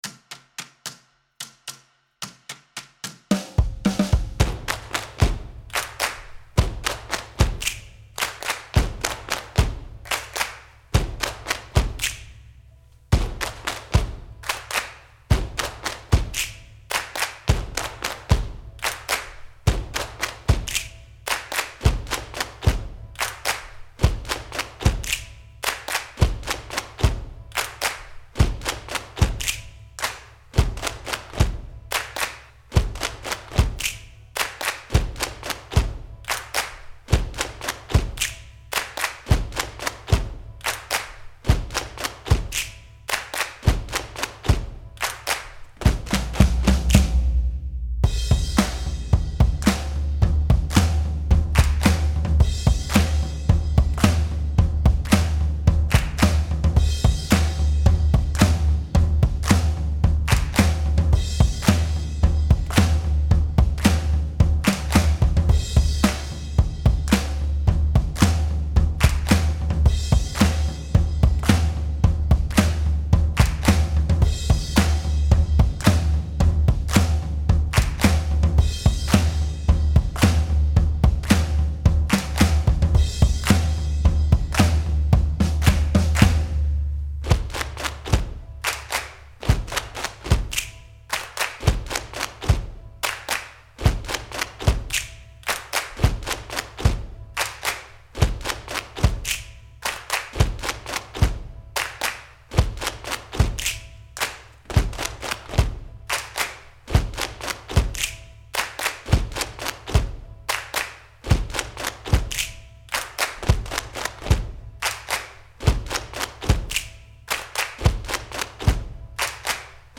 Body Percussion Rehearsal Track